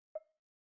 Woodblock .wav